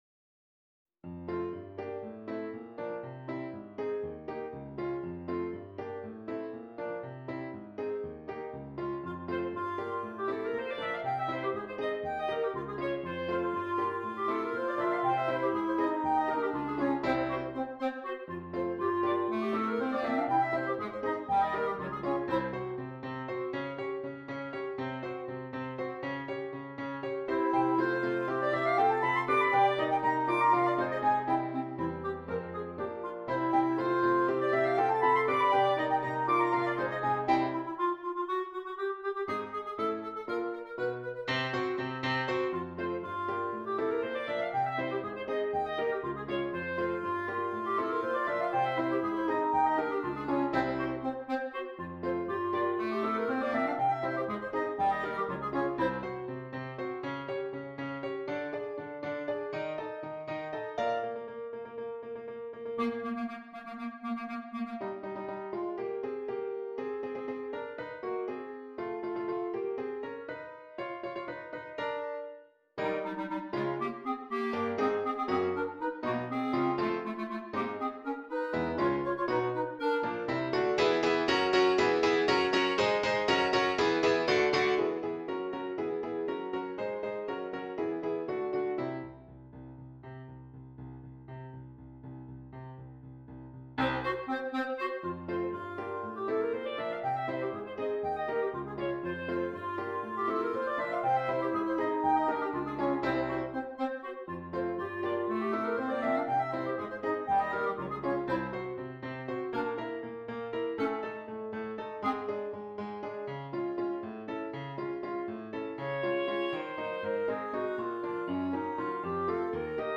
2 Clarinets and Keyboard
is a fun, lively piece